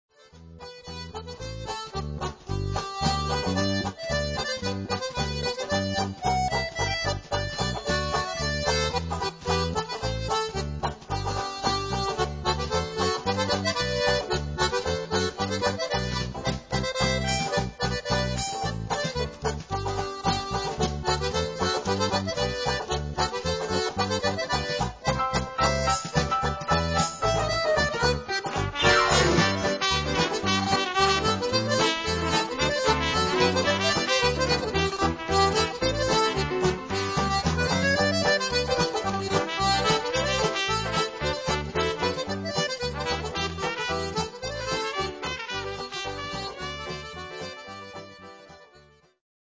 This four-part tune starts off fairly sane, but a foray into trad jazz ensues, and then things get even more out of hand...